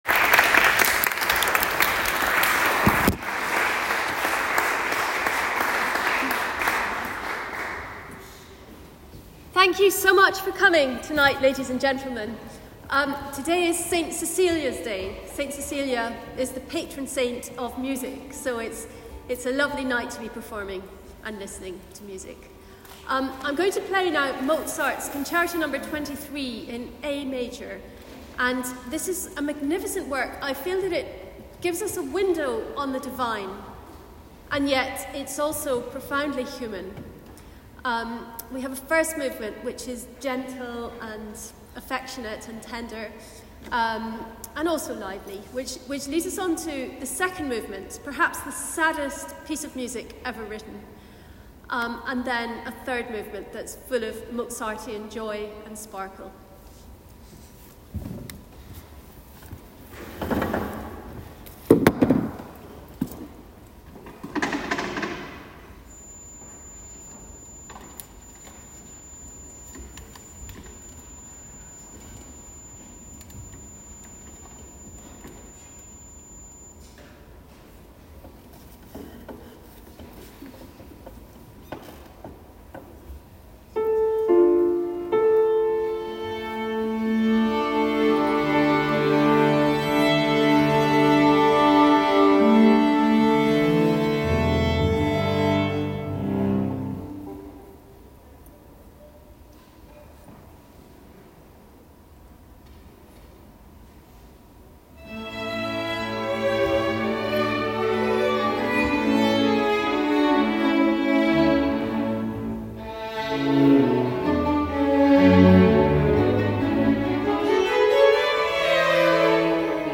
Mozart Piano Concerto no 23 in A major K488 with Piccadilly Sinfonietta
Mozart_Piano_Concerto_no_23 _in_A_major_K488_with_Piccadilly_Sinfonietta.M4A